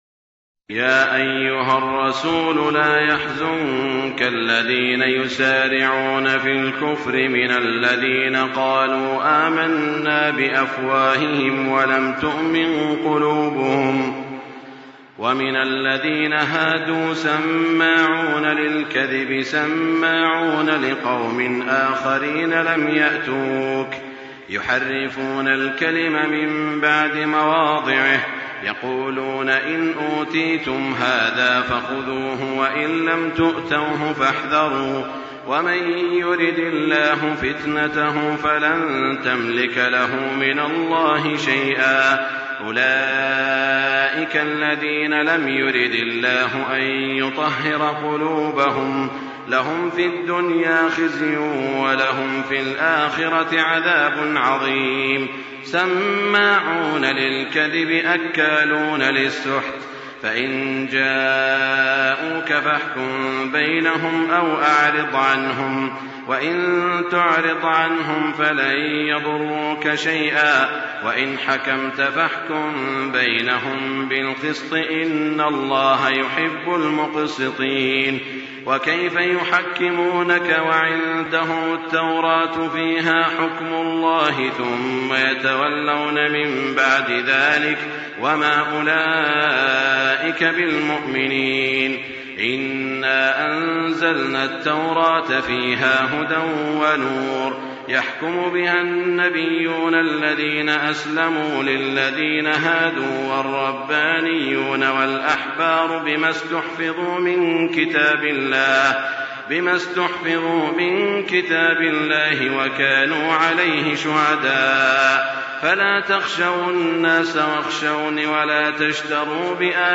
تراويح الليلة السادسة رمضان 1424هـ من سورة المائدة (41-104) Taraweeh 6 st night Ramadan 1424H from Surah AlMa'idah > تراويح الحرم المكي عام 1424 🕋 > التراويح - تلاوات الحرمين